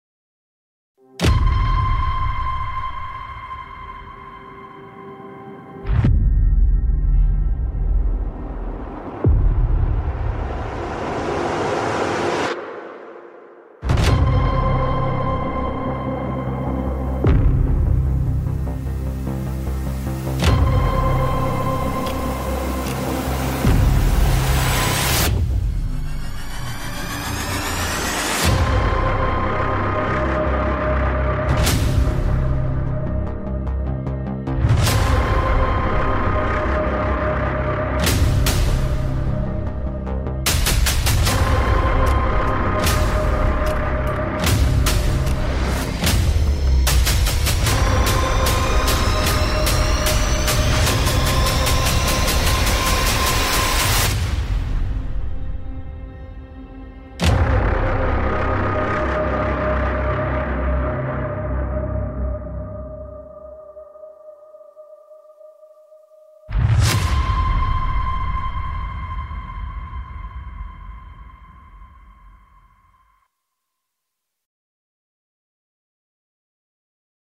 Thể loại: Hiệu ứng âm thanh
sfx-le-tuan-khang-cinematic-tension-trailer-www_tiengdong_com.mp3